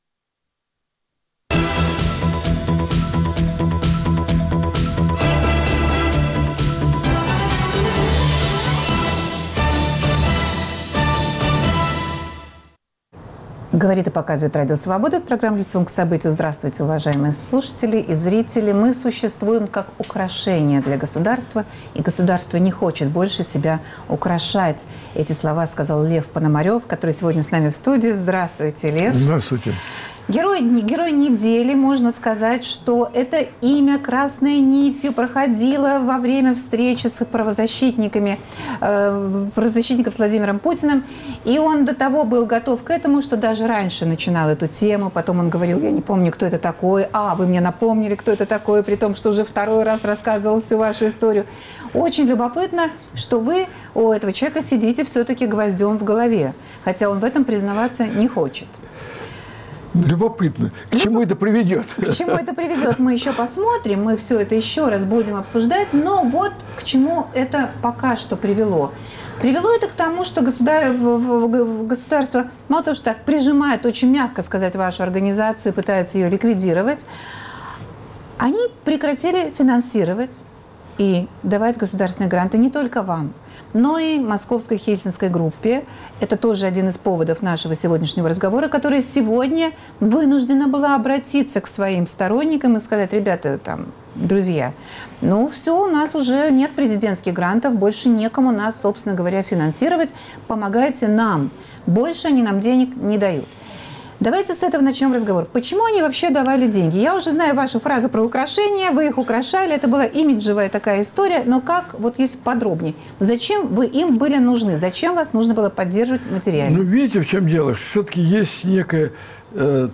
Как выживать этим движениям, если других спонсоров. кроме того же государства, в России им фактически не найти? В разговоре участвуют: Лев Пономарев